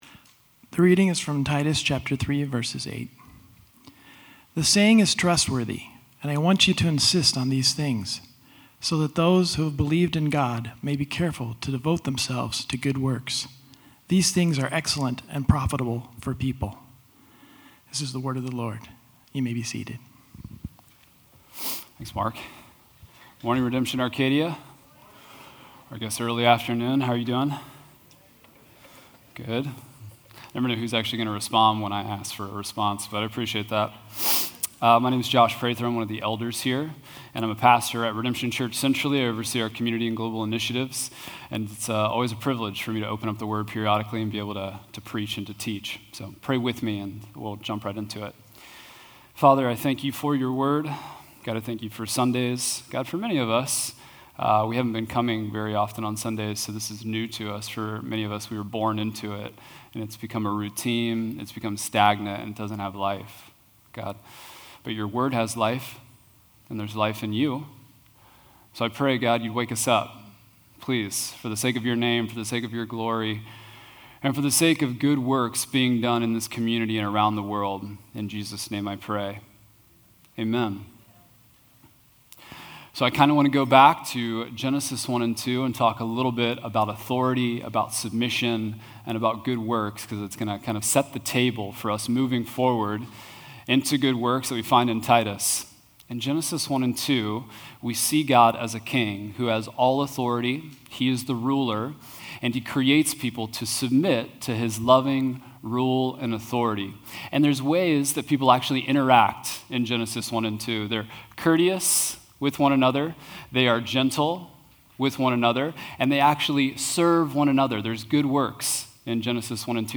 Titus: Saved for Works (Redemption Arcadia Sermons)